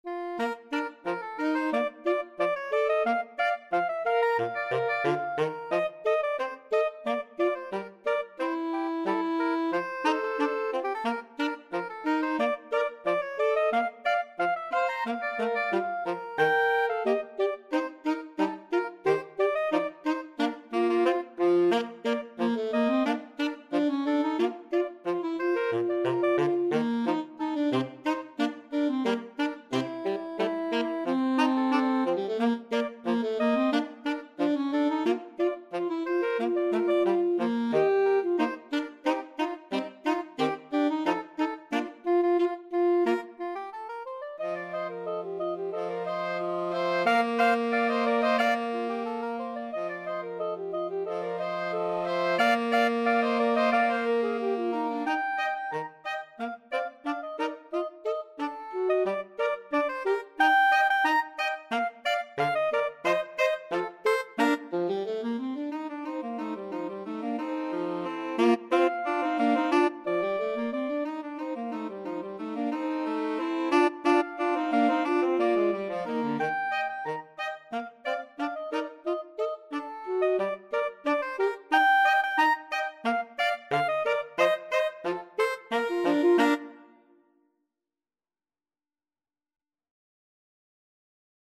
Free Sheet music for Woodwind Trio
Soprano SaxophoneAlto SaxophoneTenor Saxophone
Bb major (Sounding Pitch) (View more Bb major Music for Woodwind Trio )
Traditional (View more Traditional Woodwind Trio Music)